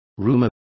Complete with pronunciation of the translation of rumors.